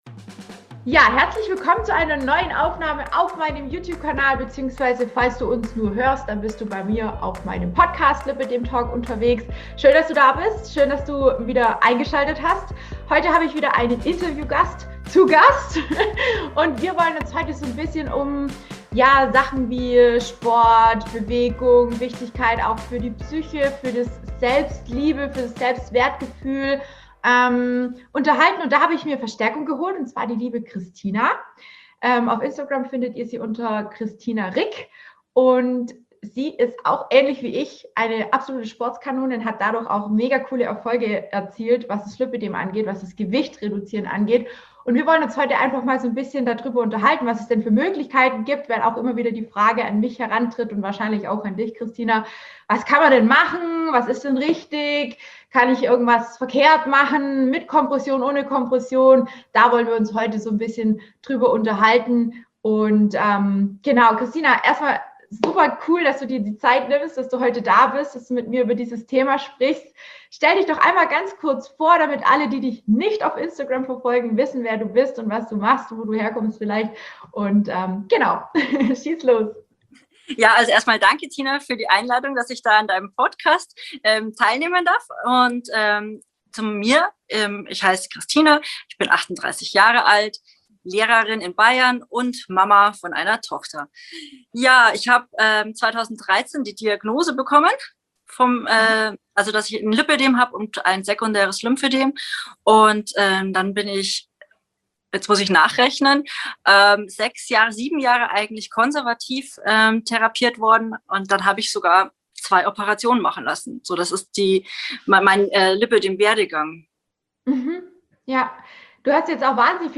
Ein sehr spannendes Interview